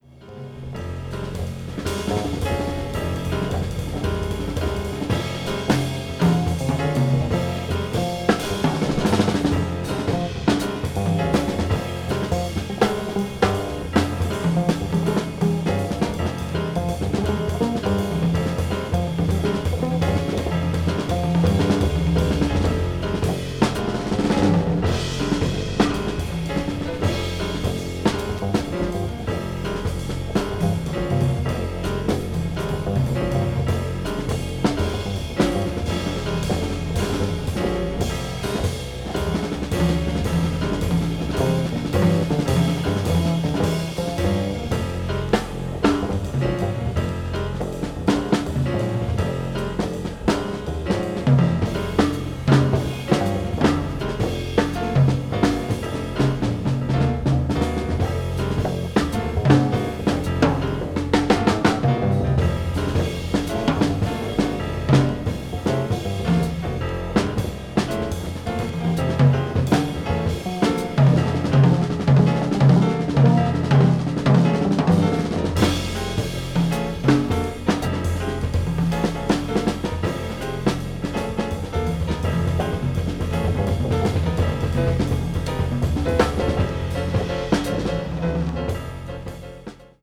avant-jazz   deep jazz   post bop   spiritual jazz